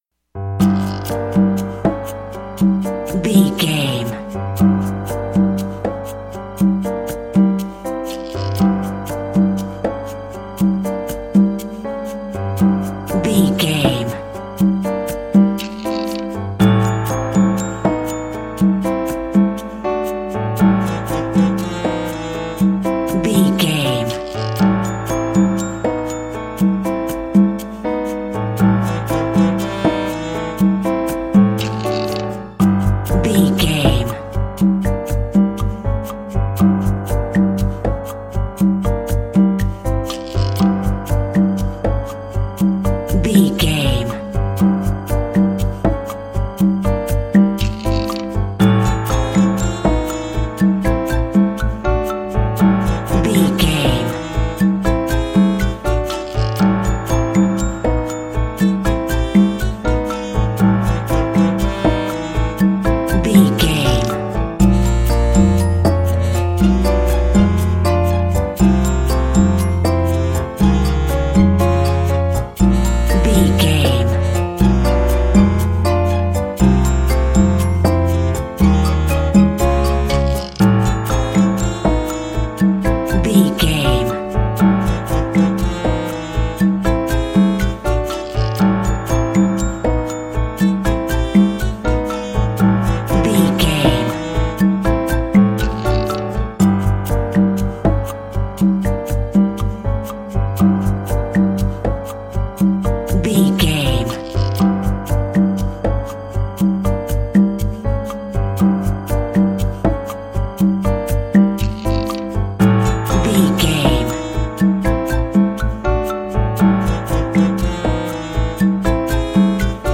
Aeolian/Minor
piano
percussion
flute
silly
circus
goofy
comical
cheerful
perky
Light hearted
quirky